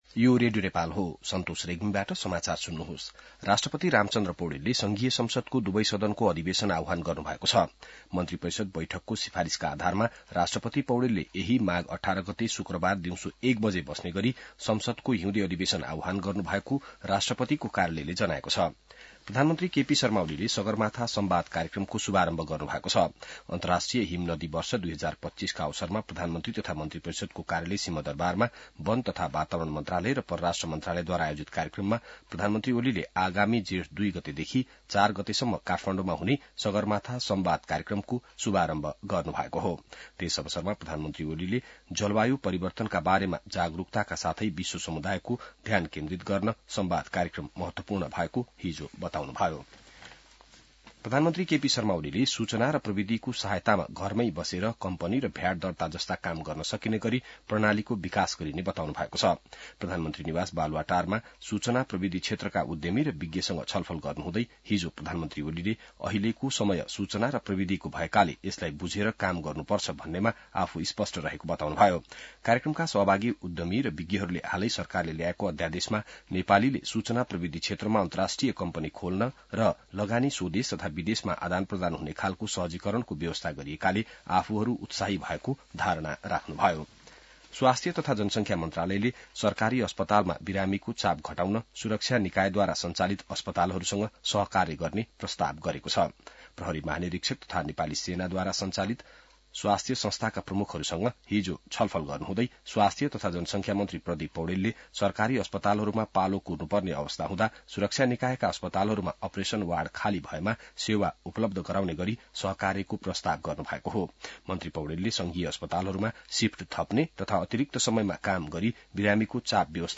बिहान ६ बजेको नेपाली समाचार : १० माघ , २०८१